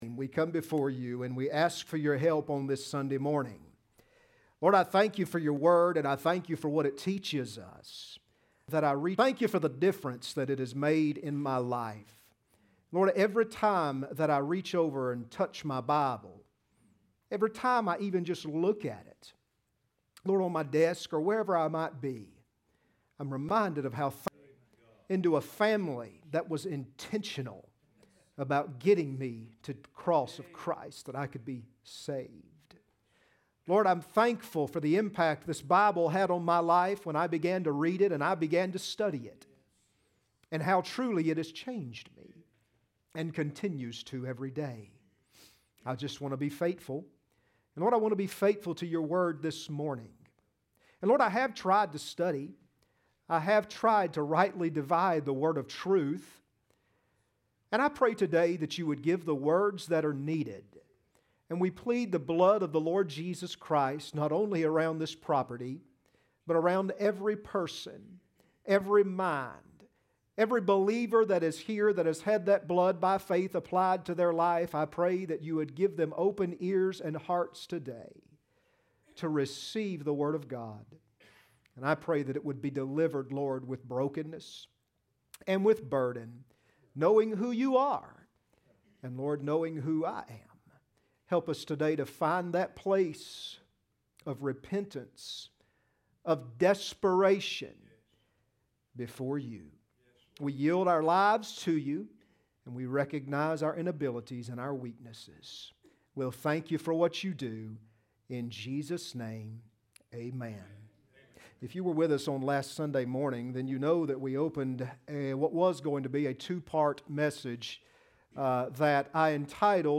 Ephesians 6:10-14 Service Type: Sunday Morning Next Sermon